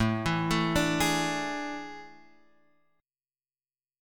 A Major 7th Suspended 4th